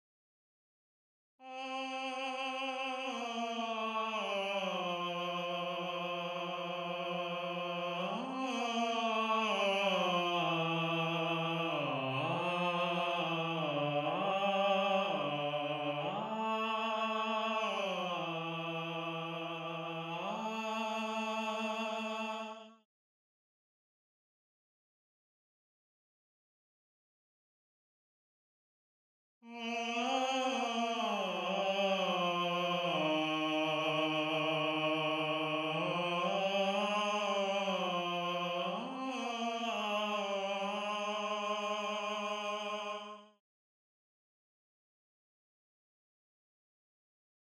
Voice 6 (Tenor/Tenor)
gallon-v8sp5-22-Tenor_1.mp3